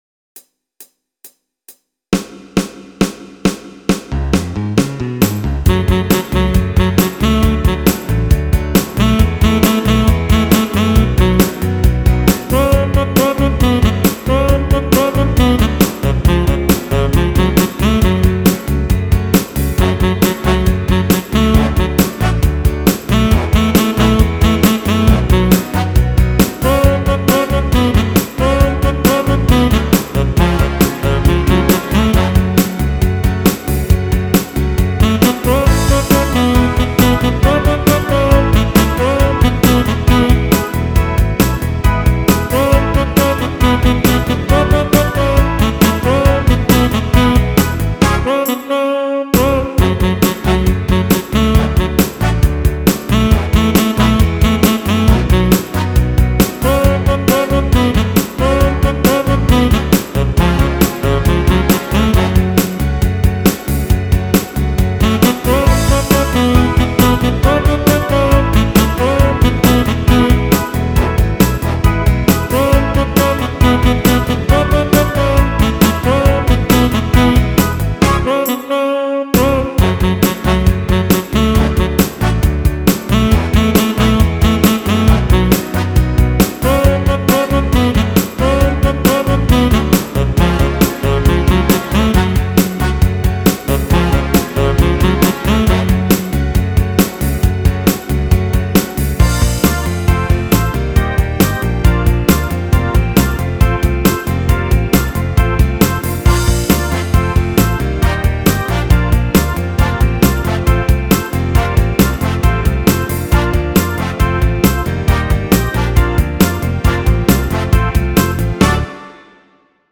(Backup tracks on the Pop/Rock list)